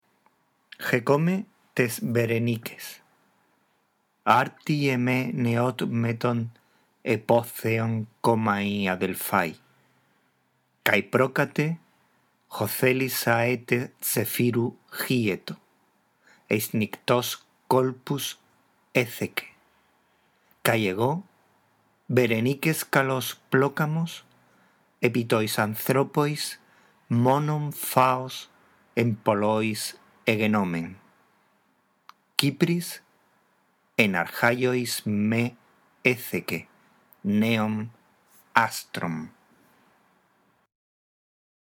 La audición de este archivo te ayudará en la práctica de la lectura del griego clásico